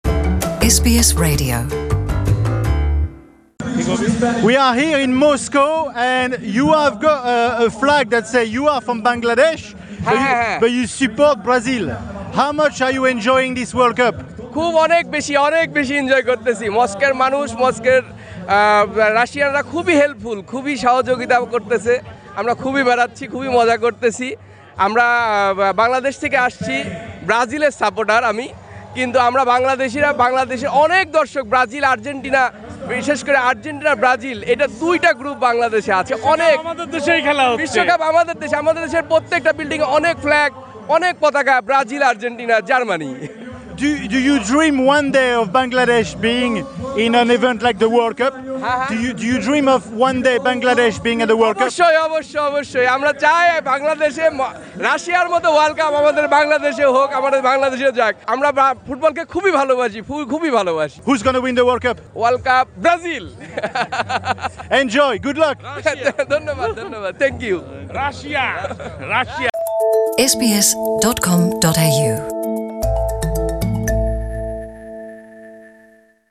Listen to the interviews (in Bangla & English) in the audio player above.